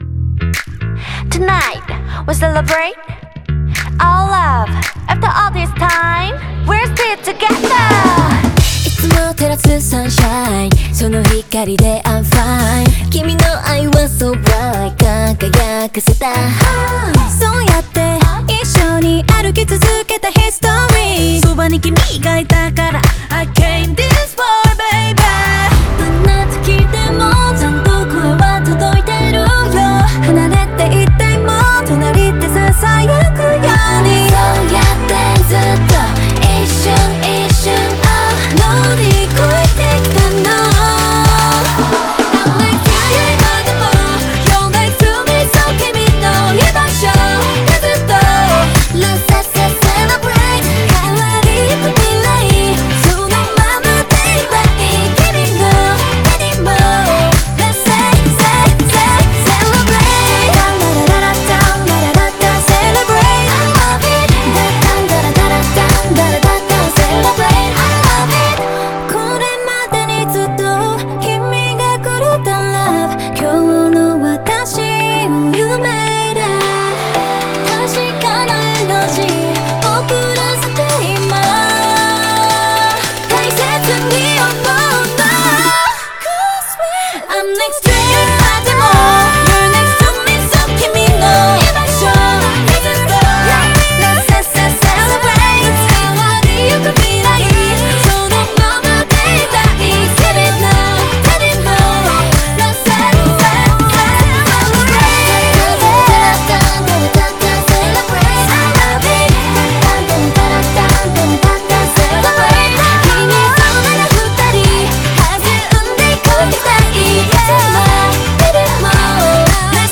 BPM112
Audio QualityPerfect (High Quality)
I'm charting K-Pop!